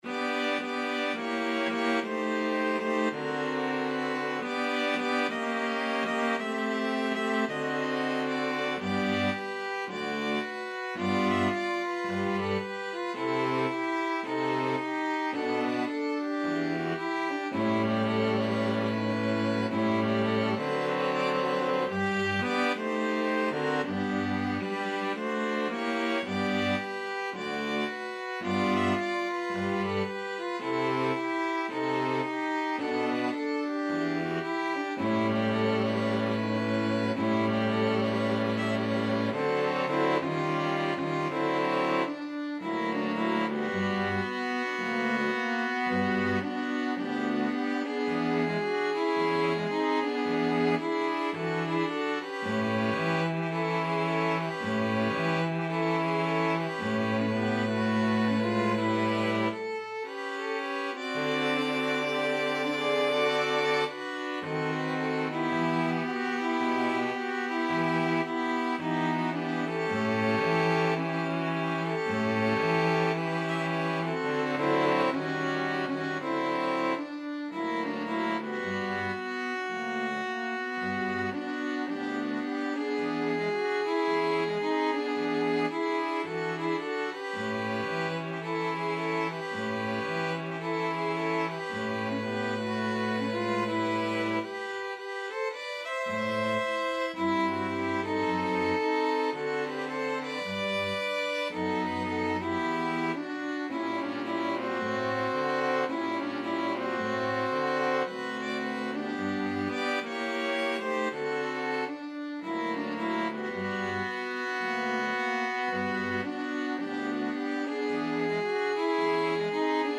Violin 1Violin 2ViolaCello
2/2 (View more 2/2 Music)
~ = 110 Moderate swing
Pop (View more Pop String Quartet Music)